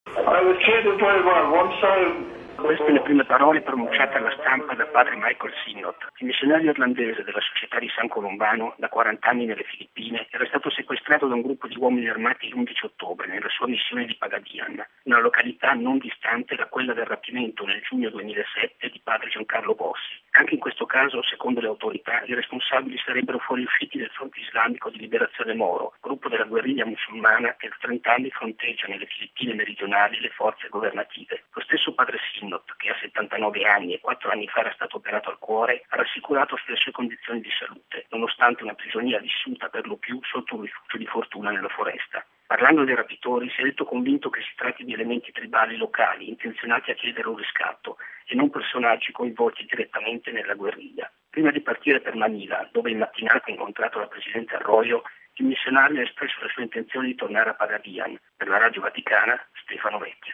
La liberazione, avvenuta a Zamboanga, sarebbe stata possibile grazie anche alla collaborazione del maggiore gruppo ribelle, quello del Fronte Moro. Il servizio